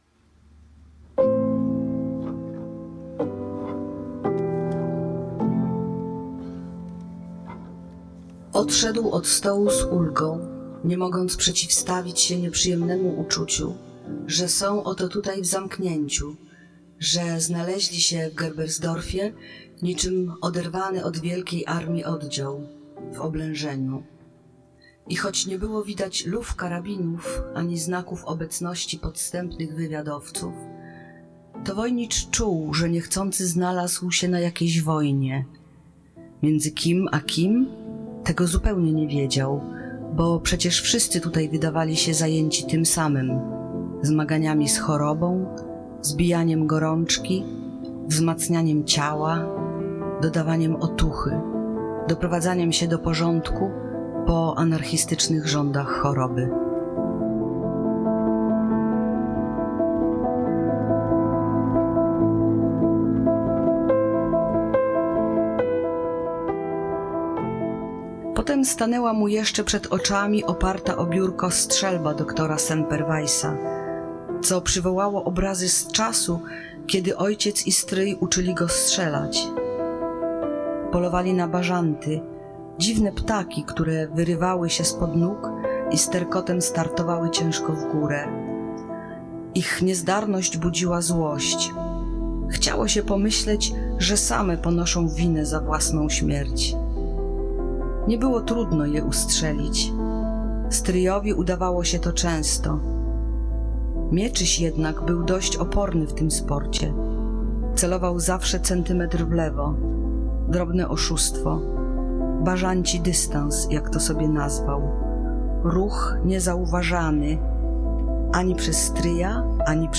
Wczoraj, 2 czerwca w Hali Stulecia miała miejsce oficjalna premiera nowej autorki Czułego narratora, Olgi Tokarczuk.
O.-Tokarczuk_czyta_fragmenty.mp3